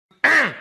Zemmour Tousse